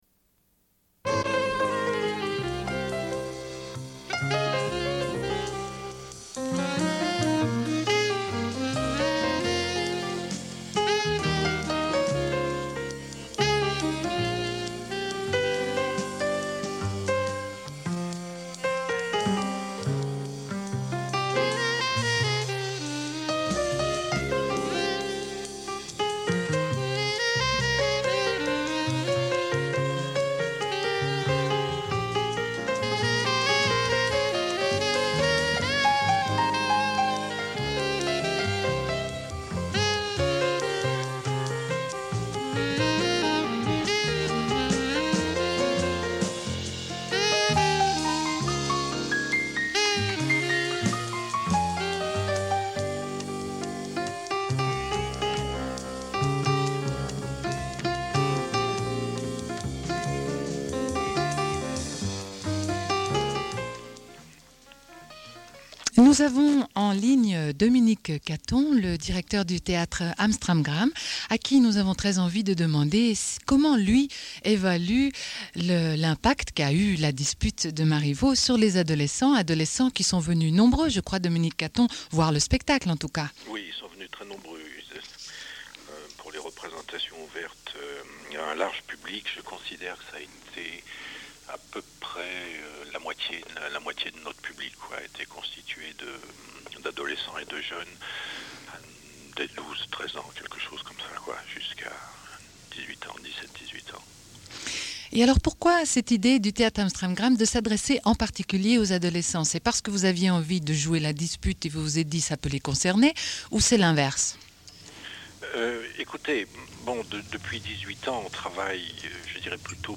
Une cassette audio, face A31:24
Sommaire de l'émission : rediffusion d'une discussion avec des adolescentes à propos de la pièce La Dispute de Marivaux, montée au Théâtre Am Stram Gram.